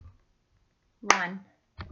Creating a Rhythm with Body Percussion
1 — Clap